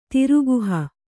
♪ tiruguha